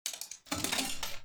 arcade-click.mp3